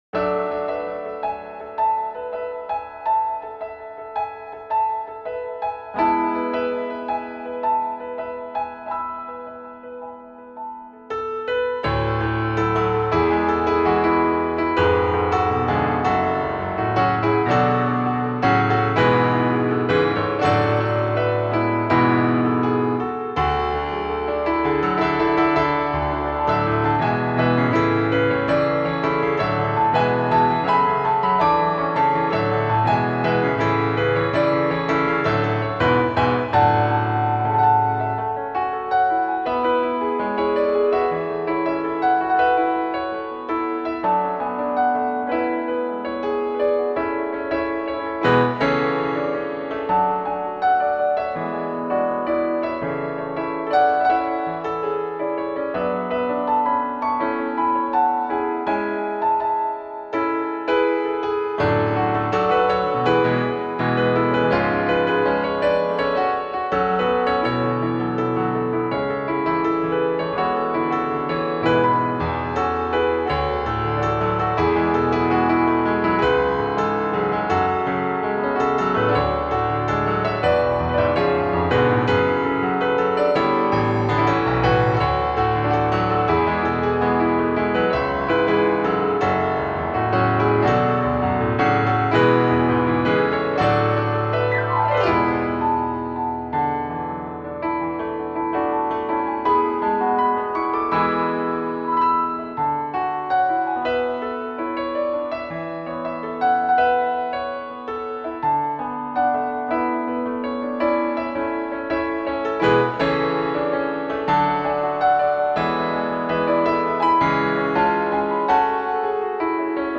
PIANO
Arreglo de piano
PIANO ARRANGEMENT